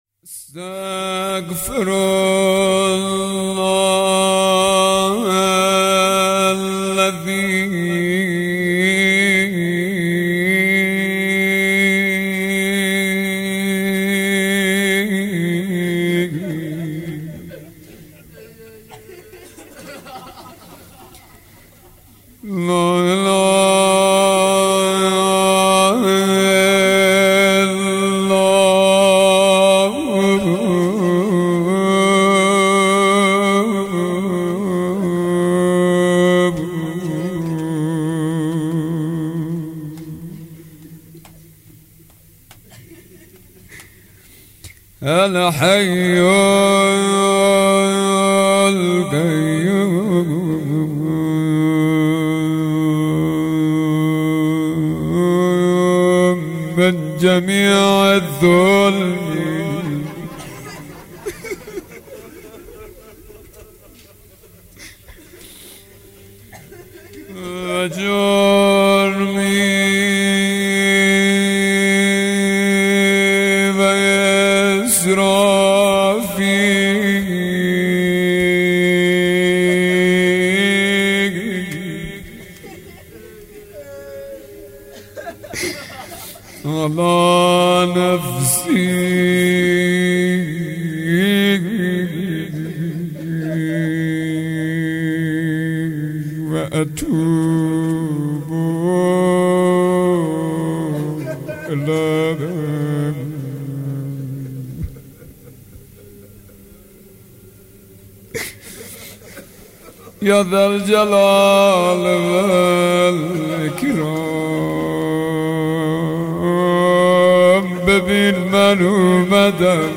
حسینیه بیت النبی
مناجات شب های جمعه